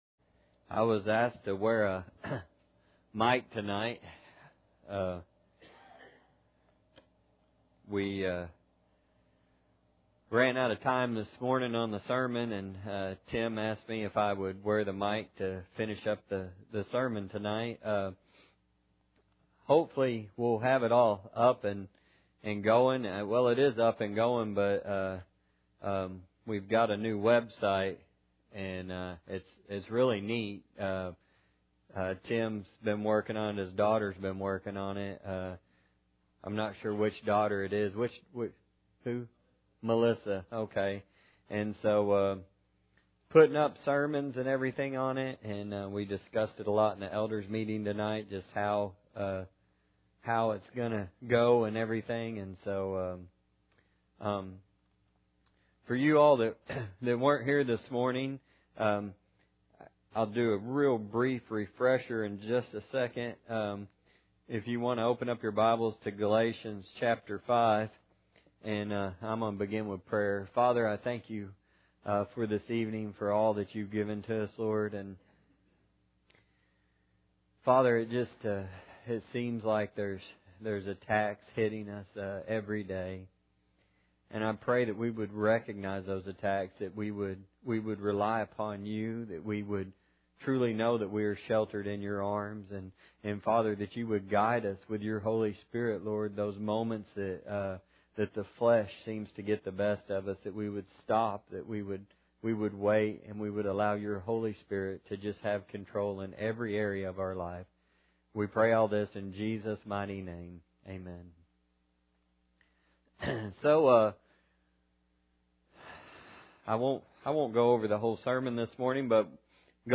Service Type: Sunday Night